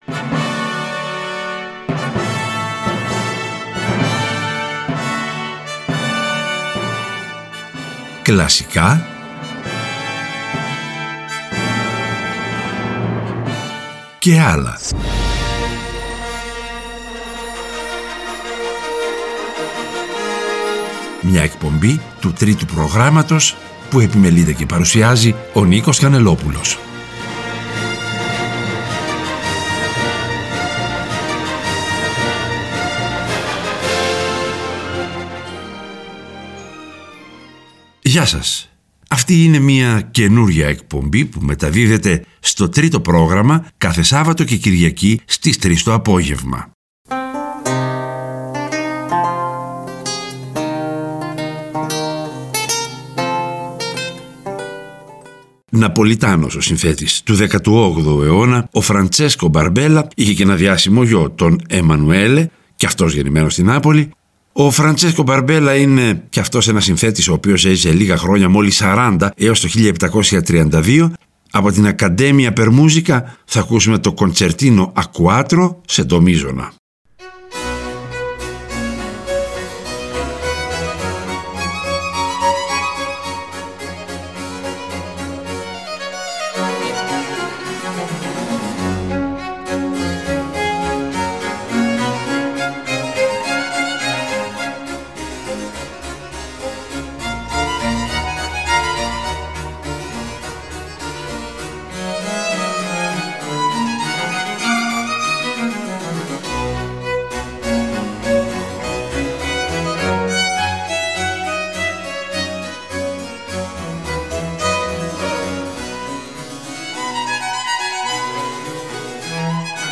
Ξεκινάμε με Μπαρόκ (Emanuele Barbella, 1718-1777 ) και κάνουμε μια σύντομη αναφορά στον Τσαϊκόφσκι.
Μια εκπομπή με το βλέμμα στη θετική πλευρά της ζωής που φιλοδοξεί να προσφέρει ενημέρωση και ψυχαγωγία, υπέροχη μουσική με σημαντικούς ερμηνευτές.